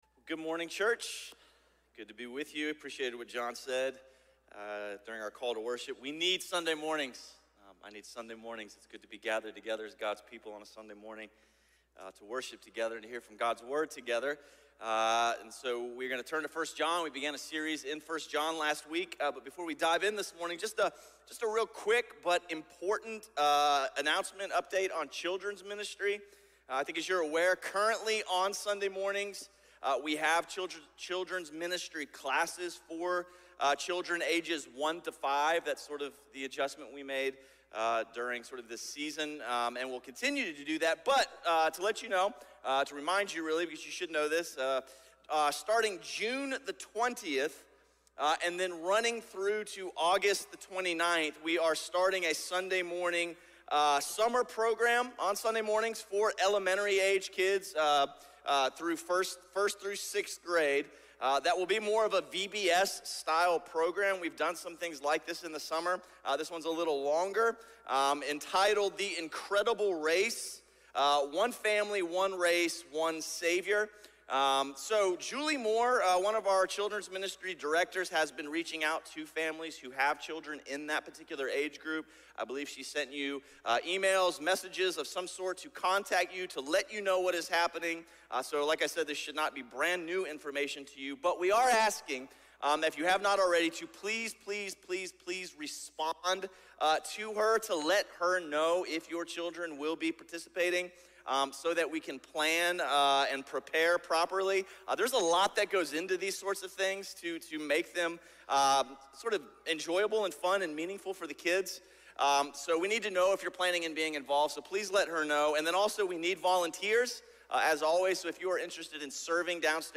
A message from the series "I Am."